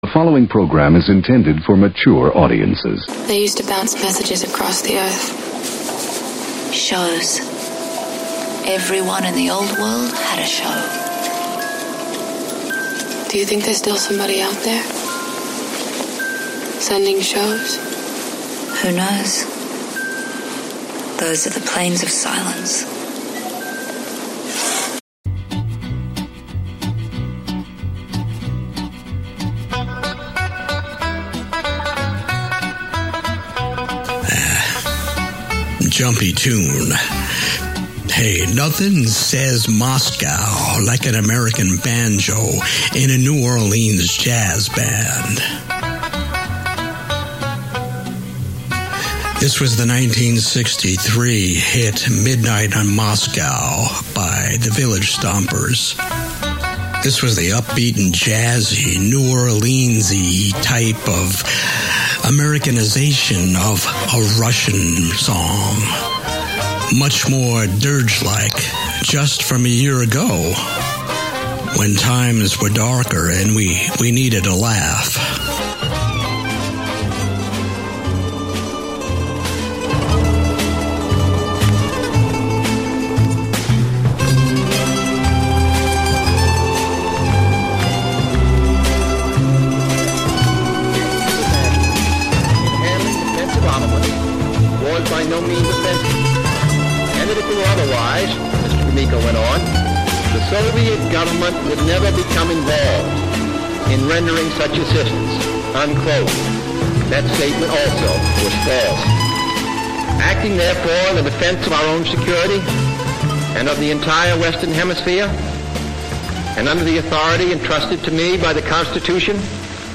broadcast , comedy